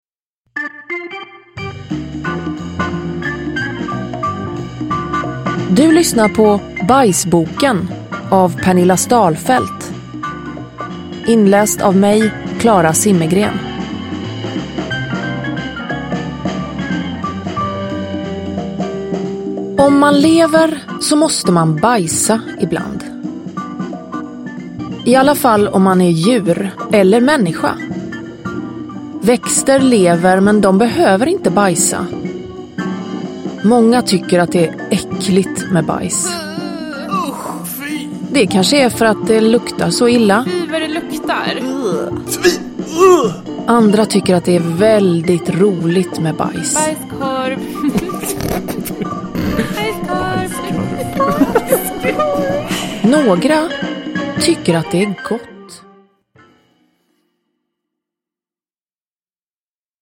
Bajsboken – Ljudbok – Laddas ner